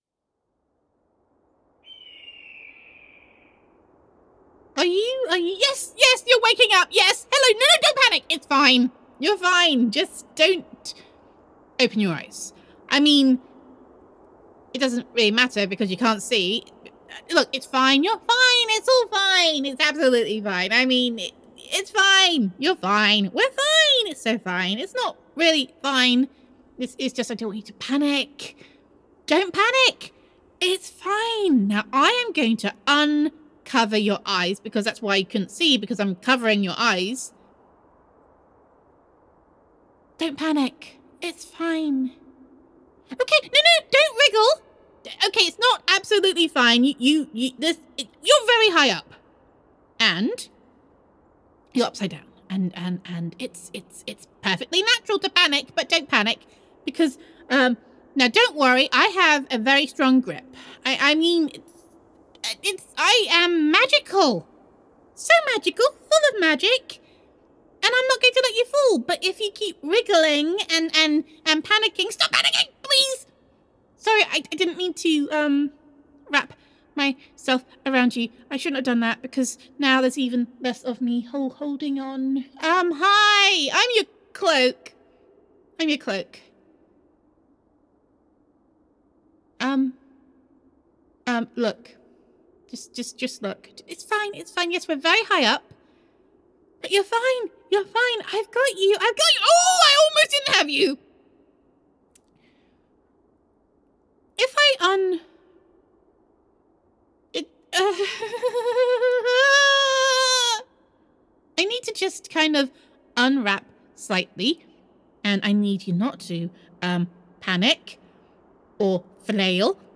[F4A] Everything Is Fine [Magical Cloak Roleplay][Sorcerer Supreme Listener][Amnesia][I Gesture a Lot][Hopefully Not Plummeting to Your Death][The Scary Witch Does Not Like You Very Much][I’m Magical][I Am Not a Magic Shield][Look at You Remembering Words][Even if They Are Bad Words][Gender Neutral][As Their Magical Cloak Struggles to Keep Hold, the Sorcerer Supremes’ Fate Hangs in the Balance]